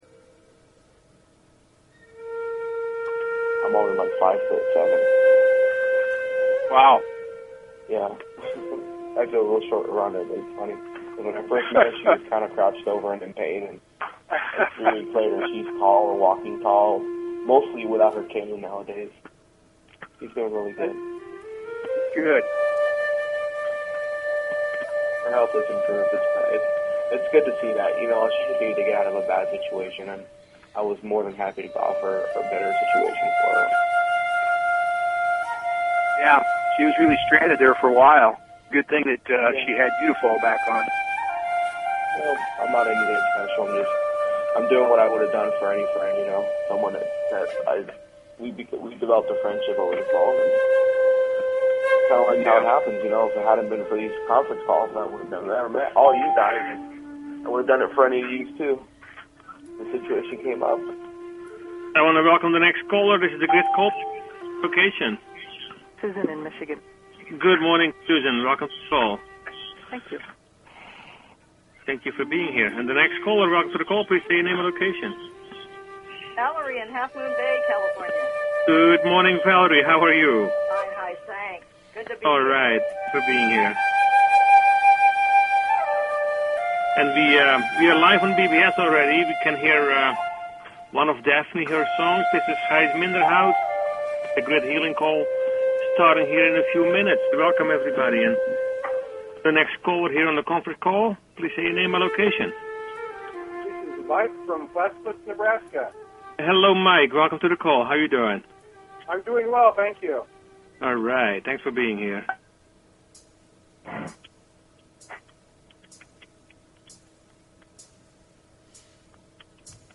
Personal Planetary Healing Meditation